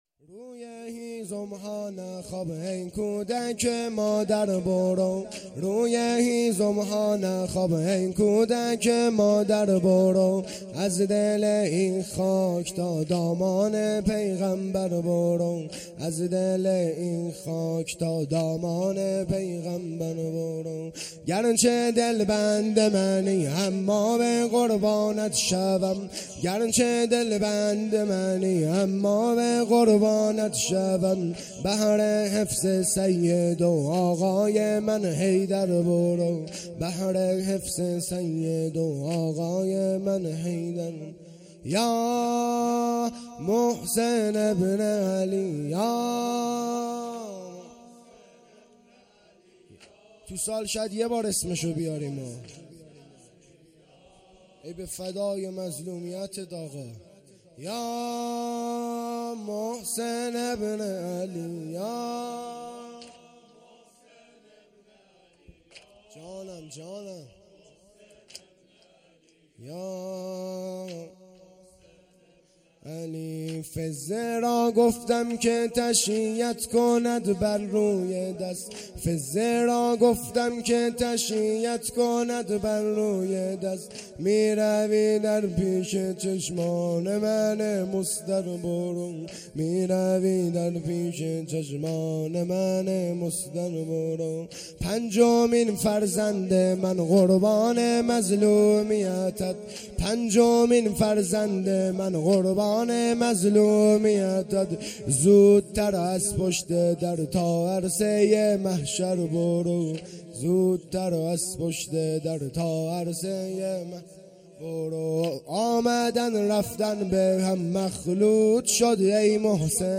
چهارشنبه ۲۱ مهرماه ۱۴۰۰ - هیئت جوانان ریحانه الحیدر سلام الله علیها
ایام شهادت امام حسن عسکری ۱۴۰۰